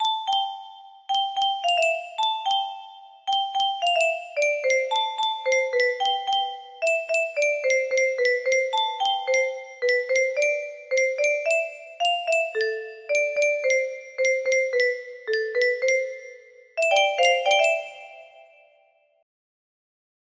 10517 music box melody